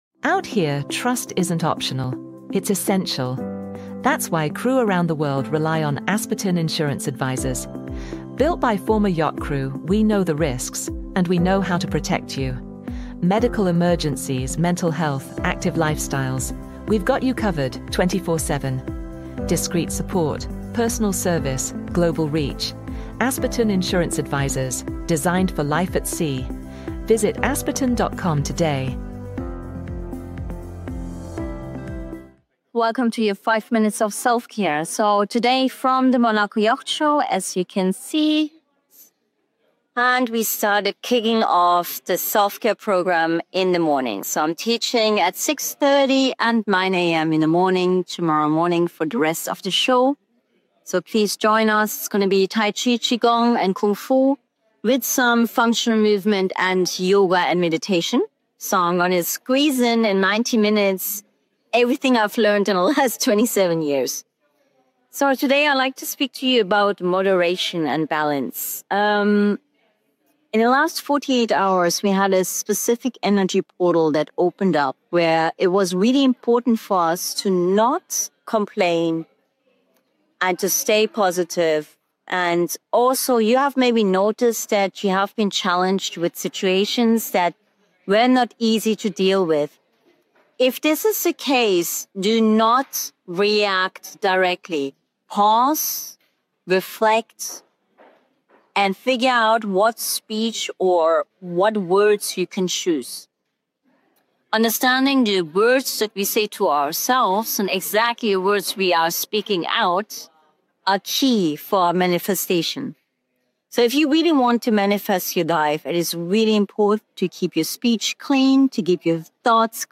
🌍 Live from the Monaco Yacht Show